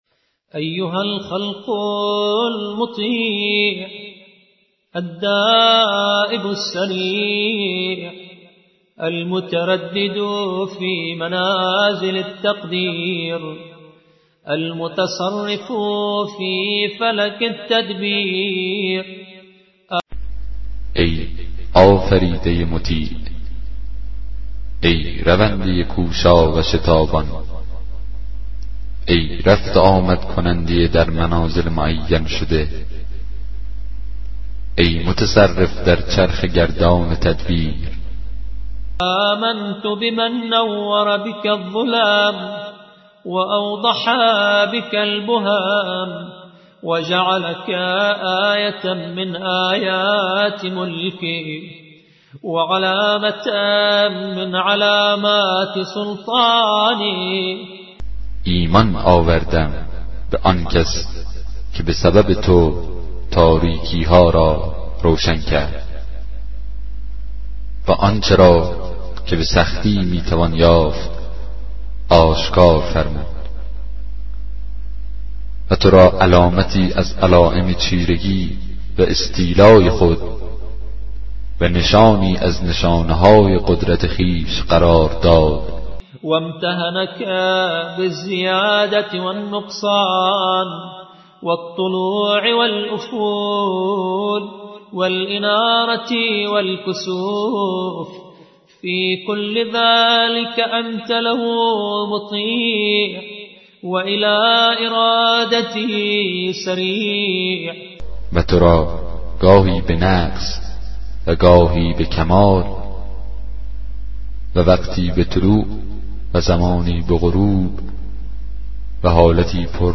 کتاب صوتی دعای 43 صحیفه سجادیه